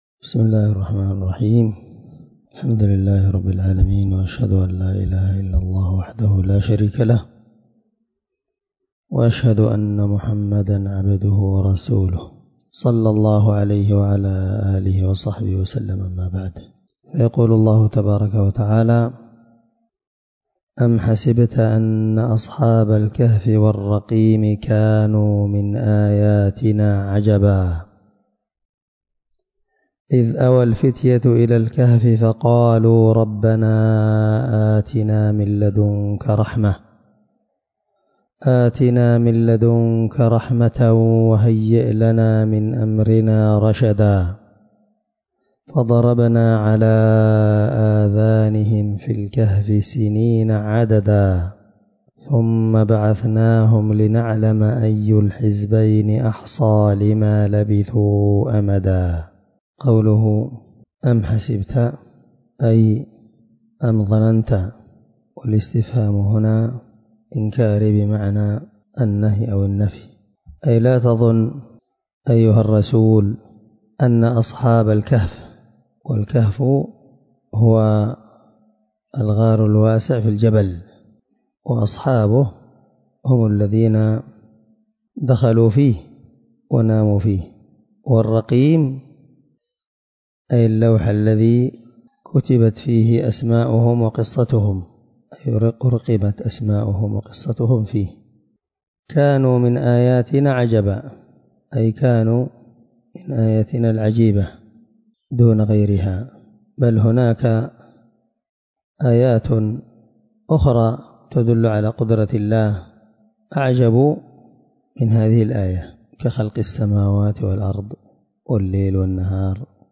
الدرس3 تفسير آية (9-12) من سورة الكهف